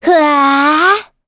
Gremory-Vox_Casting2.wav